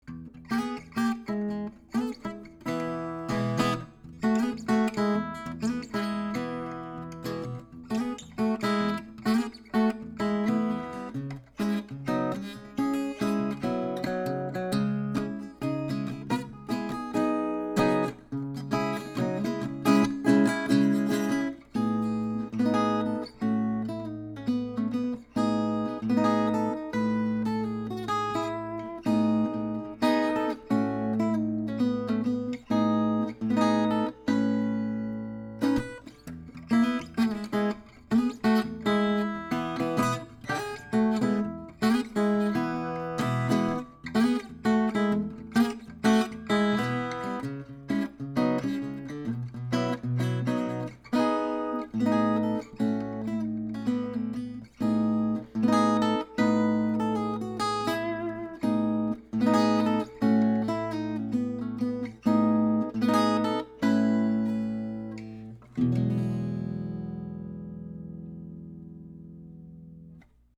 Archtop
Guitar
The high end is accuentated, and the transients are quick.
I used a SAMAR/AKG C1000s condenser mic going into a Sony PCM D1 flash recorder, with no reverb, eq or any other effects.
(Fast chords in Em)